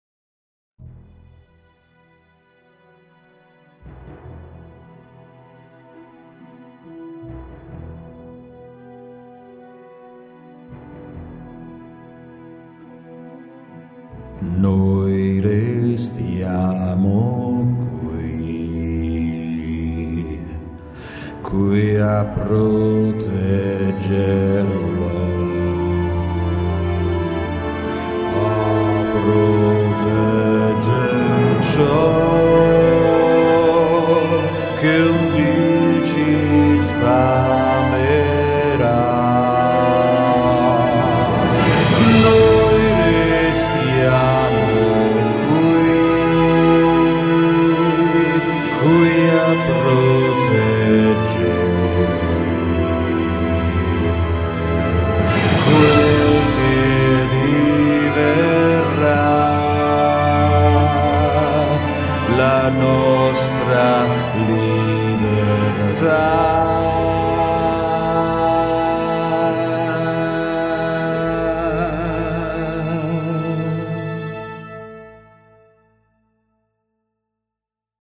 UOMINI
basso.mp3